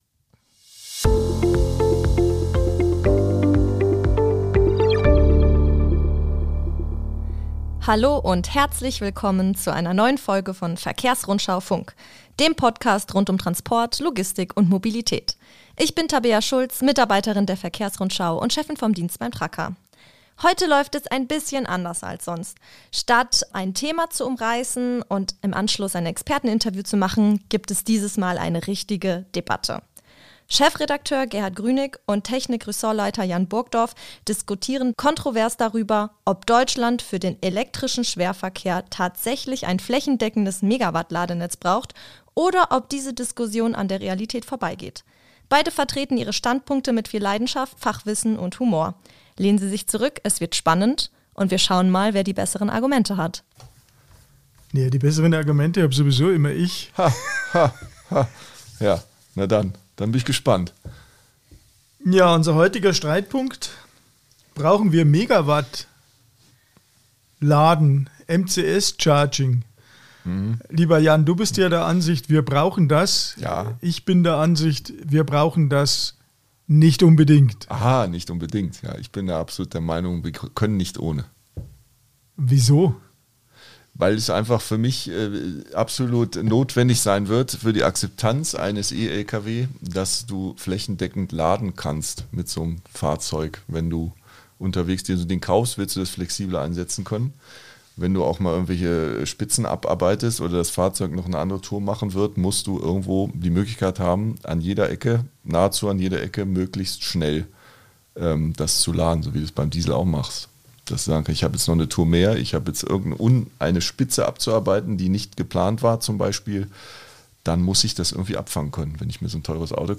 Eine pointierte, humorvolle und fachlich dichte Debatte über Reichweite, Batterietechnik, Kosten, Ladeinfrastruktur und die Frage, wie Deutschland seine E‑Lkw‑Zukunft wirklich gestalten sollte.